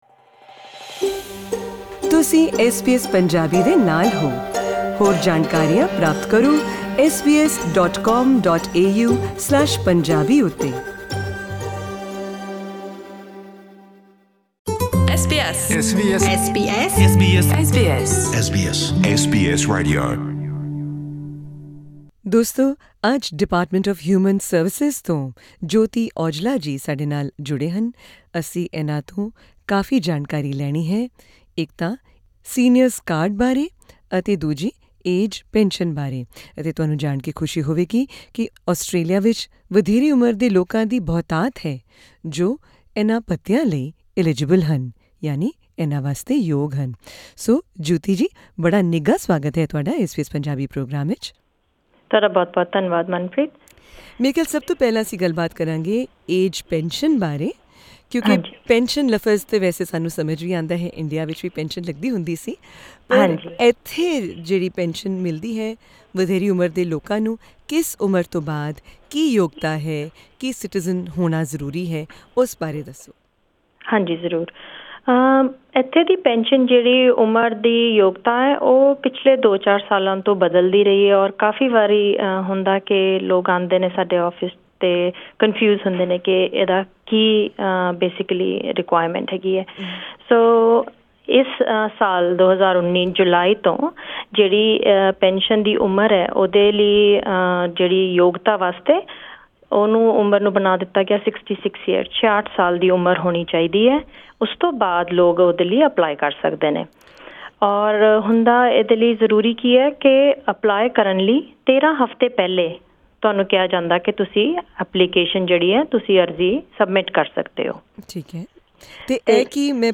In a special interview with SBS Punjabi